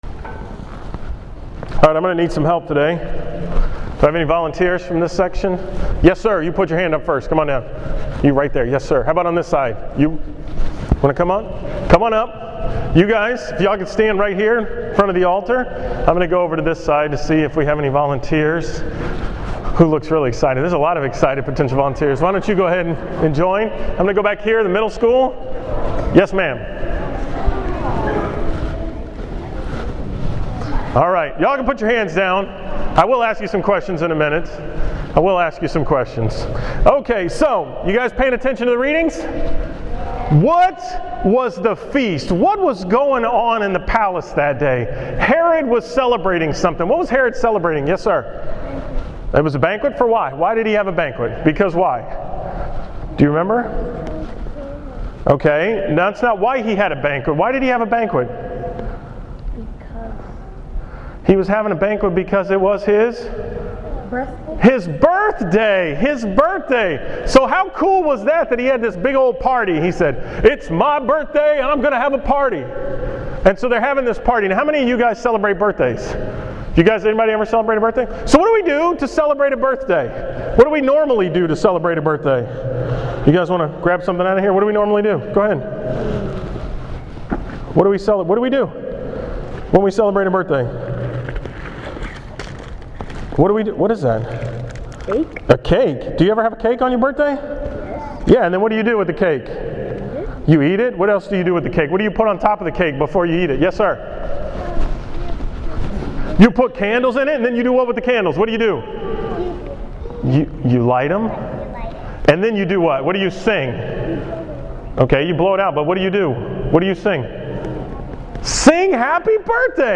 From the School Mass at St. Thomas More on August 29, 2013
Category: 2013 Homilies, School Mass homilies